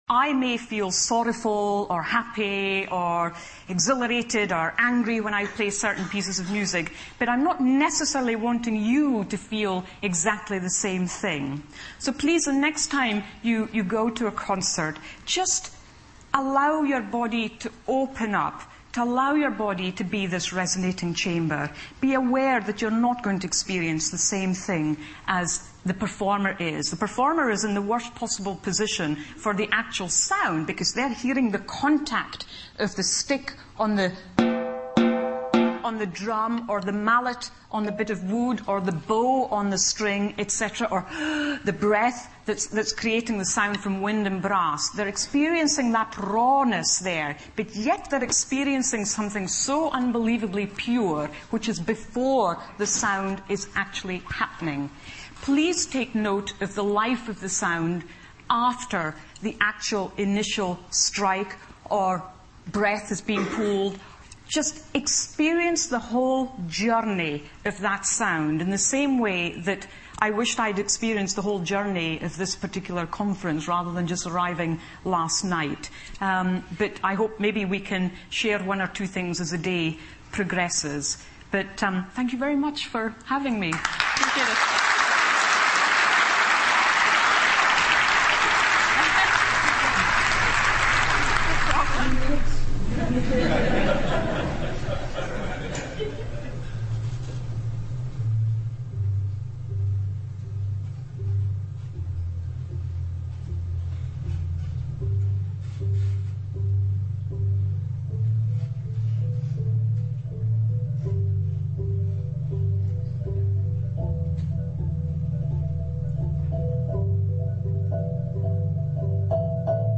TED演讲:关于听的艺术(15) 听力文件下载—在线英语听力室